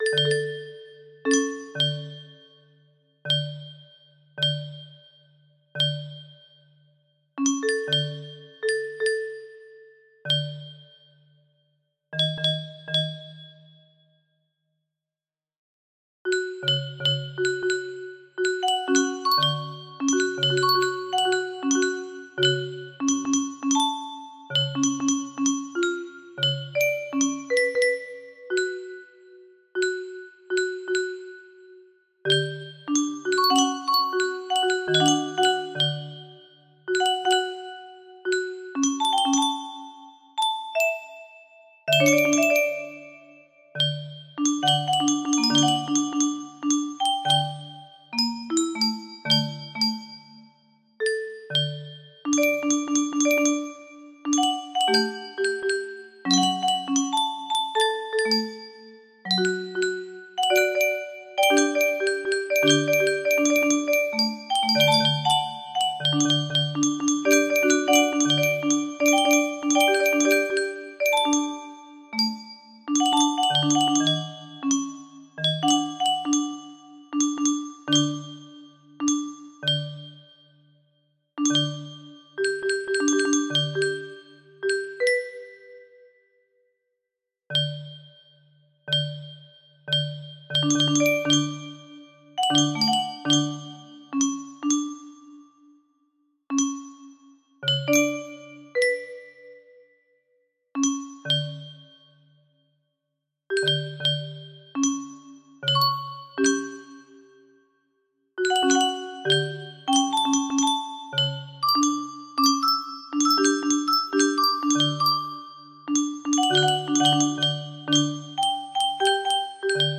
Om So Hum music box melody
Full range 60